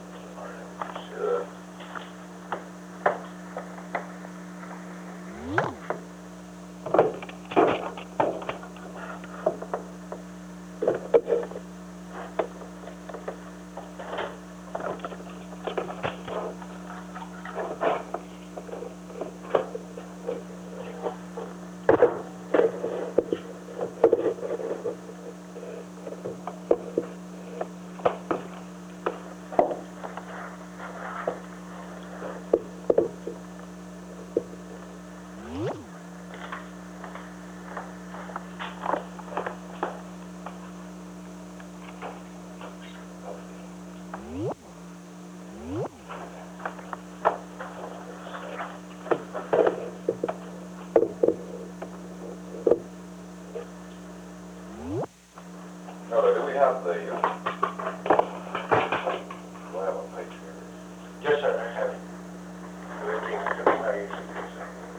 Secret White House Tapes
Conversation No. 241-7
Location: Camp David Hard Wire
The President [?] met with an unknown man.